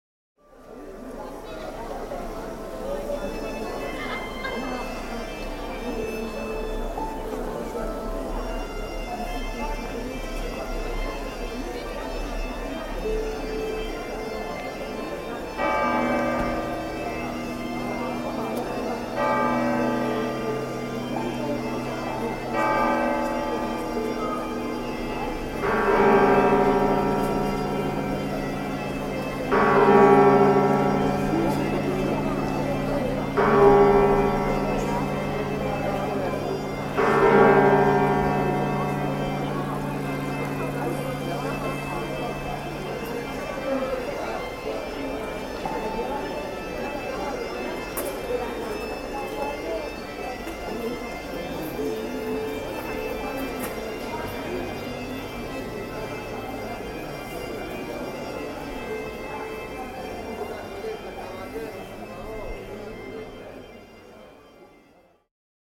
At the core of Santiago de Compostela, Praza das Praterías (Plaza de las Platerías) pulses with the sounds that embody its spiritual and cultural spirit. The resonant toll of bells from the nearby cathedral forms a solemn call, echoing across the plaza as pilgrims gather, their conversations blending into the hum of the crowd. Galician bagpipes provide a distinct, melodic layer to the atmosphere, their notes weaving through the lively surroundings and grounding the scene in regional tradition. The recording reflects the steady tread of pilgrims, their steps echoing the weight of their long journey, each footfall infused with respect and expectation.
——————— This sound is part of the Sonic Heritage project, exploring the sounds of the world’s most famous sights.